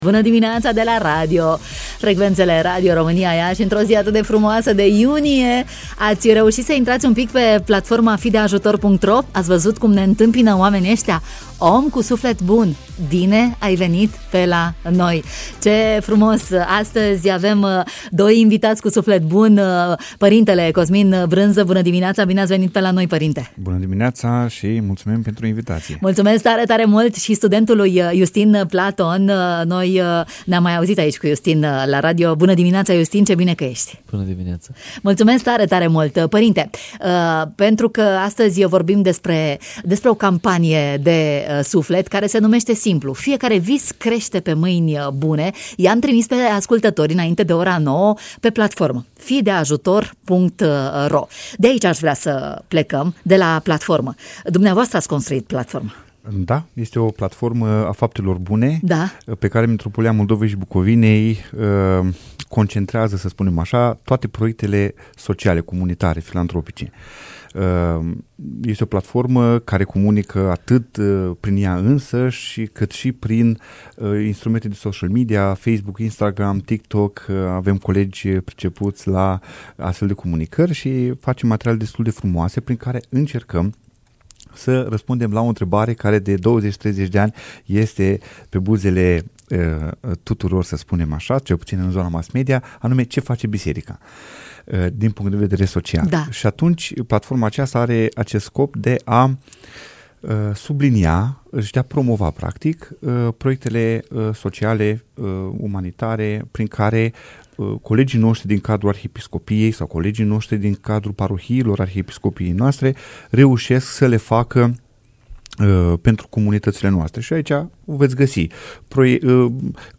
la matinalul de la Radio Iași